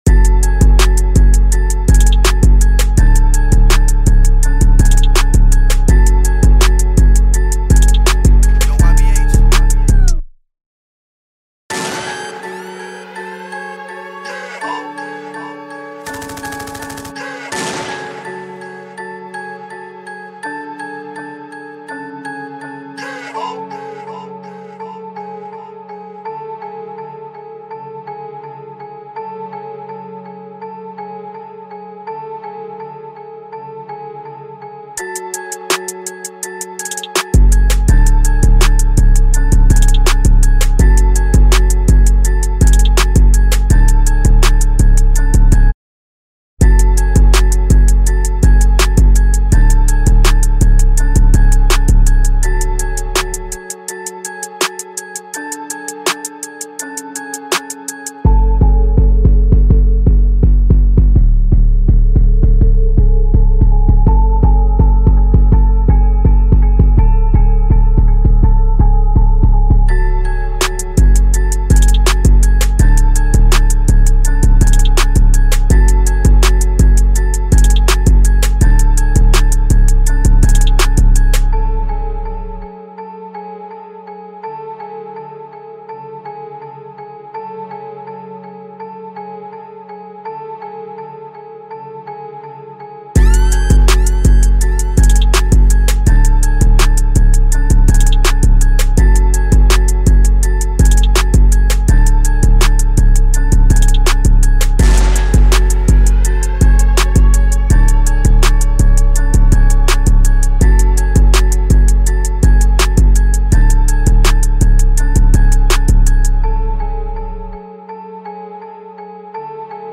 This is the instrumental of the new song.